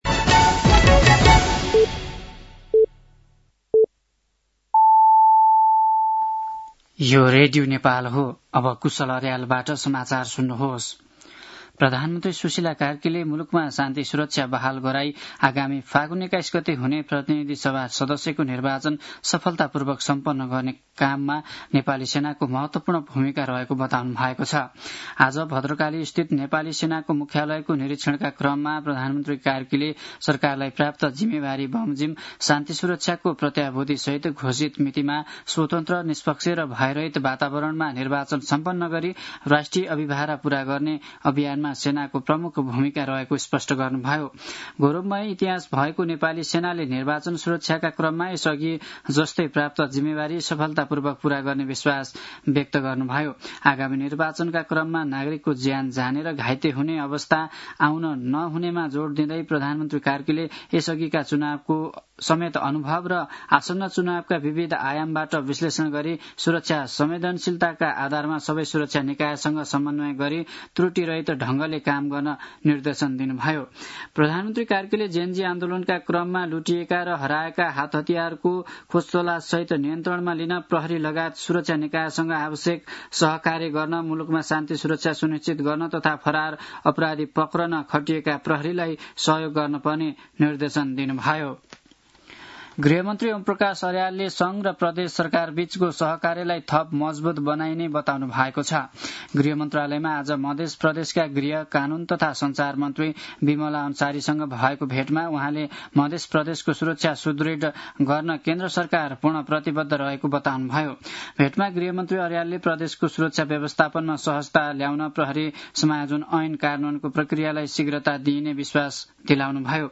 साँझ ५ बजेको नेपाली समाचार : १२ मंसिर , २०८२
5-pm-nepali-news-8-12.mp3